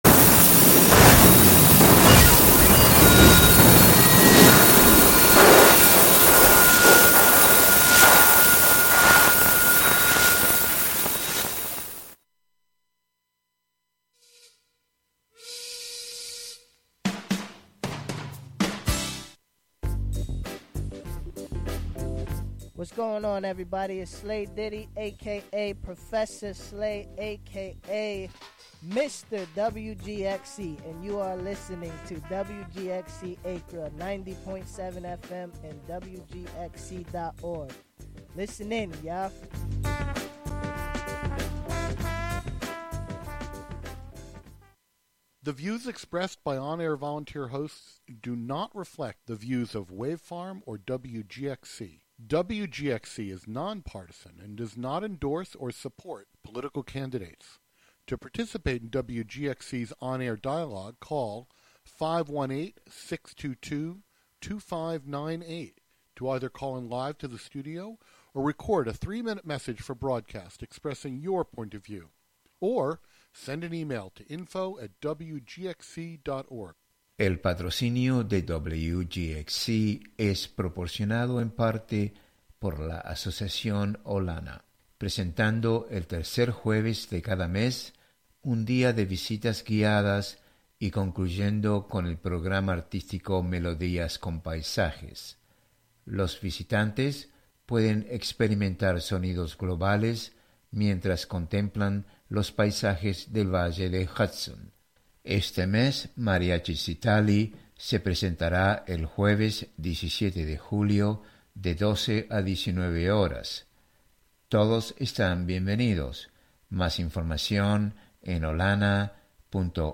Hosted by various WGXC Volunteer Programmers.
Tune in for special fundraising broadcasts with WGXC Volunteer Programmers!